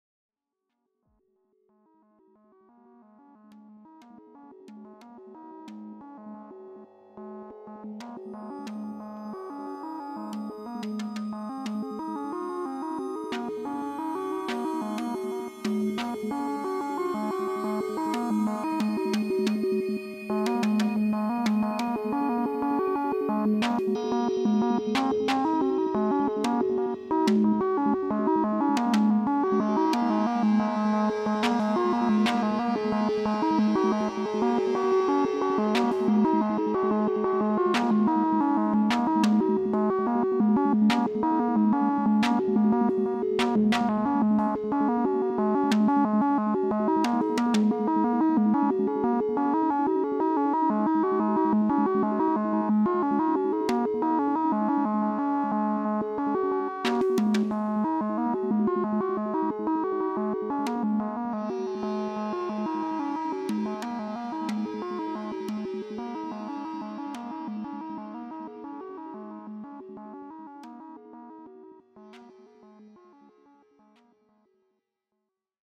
60 seconds of a new patch